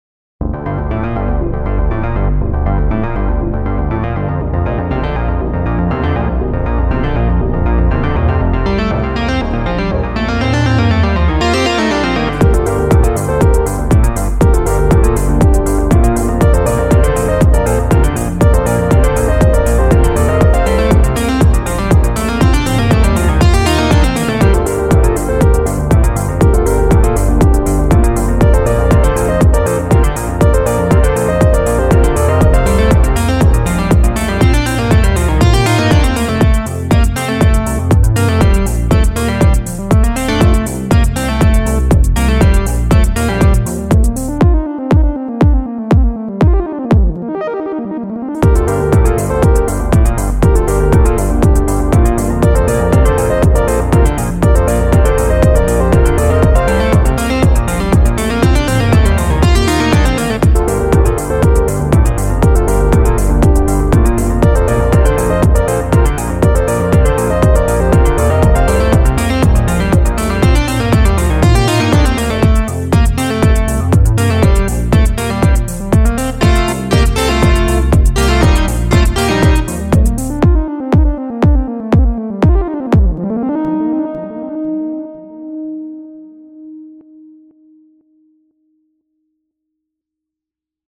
描述：舞蹈和电子音乐|放克
Tag: 合成器